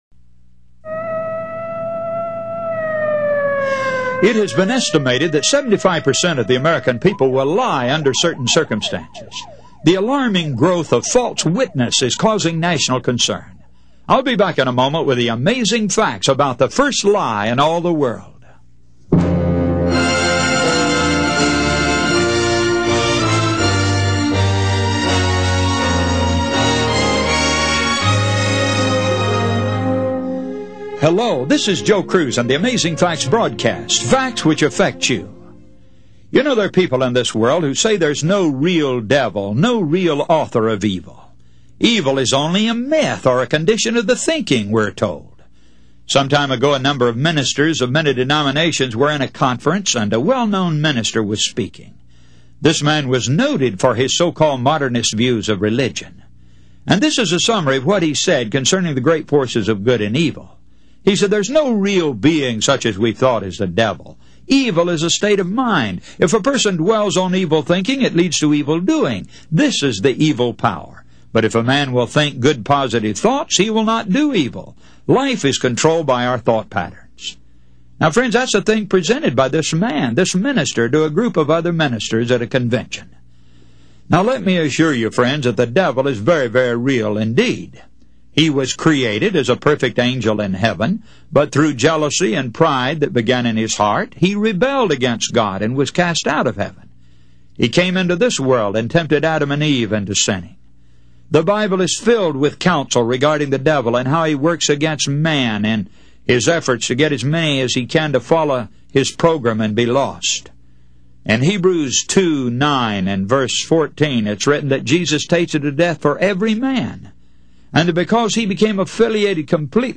Radio Sermons